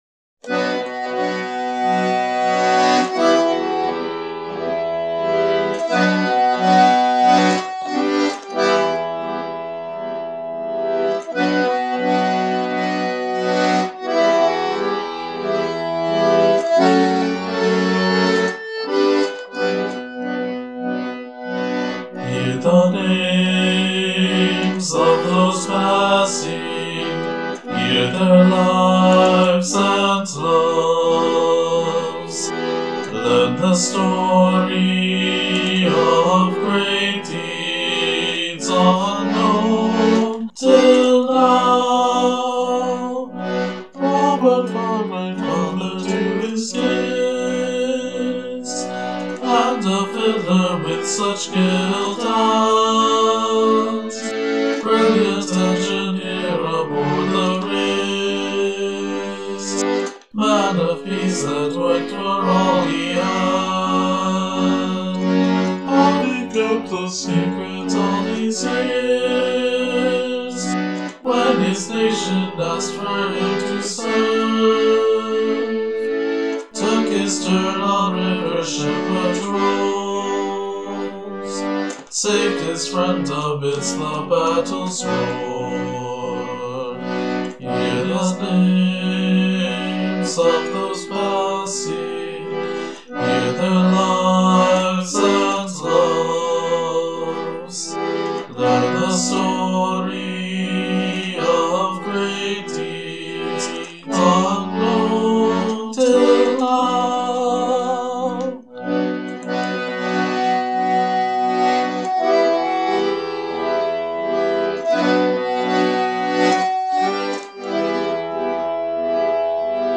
Musically, this is intentionally fairly simple. The verse runs on a chordal pattern known as the "descending fifths sequence", but beyond that there's nothing particularly unusual going on.